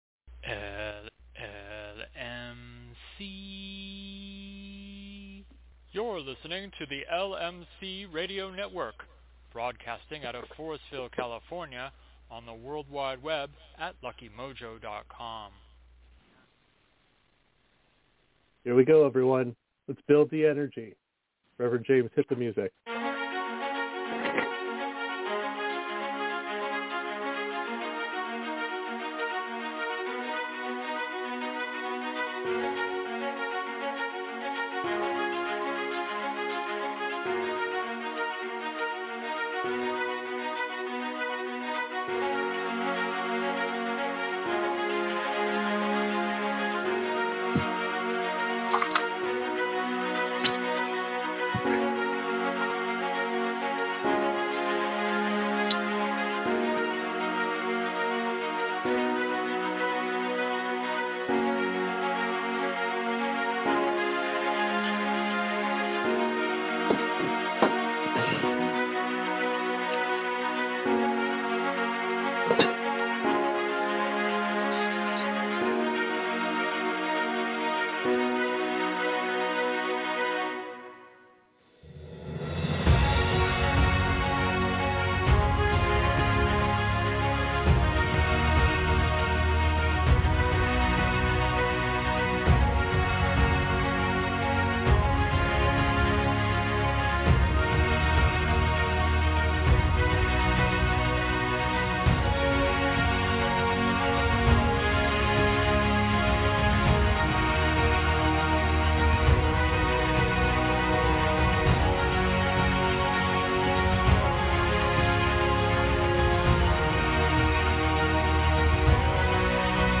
We begin this show with an interview of our guest followed by a discussion of energy work and it's use in maintaining health as well as to bring success and prosperity. What is energy work?